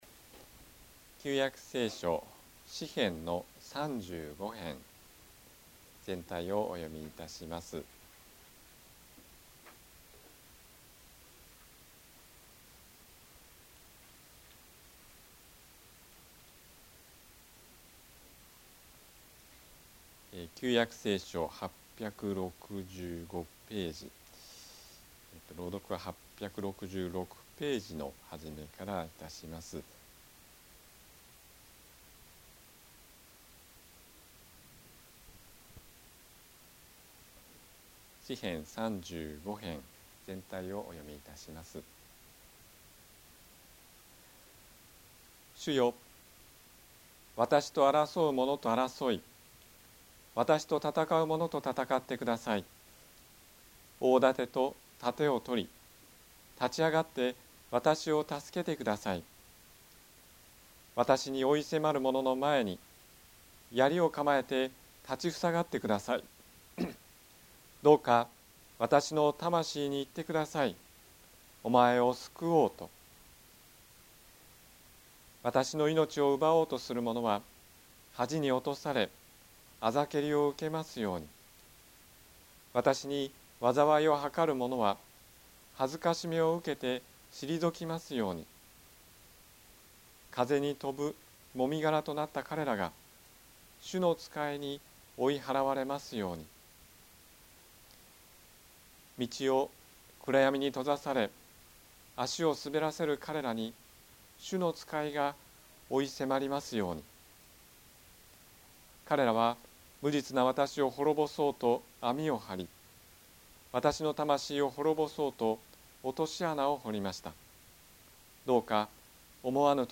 日曜 朝の礼拝
説教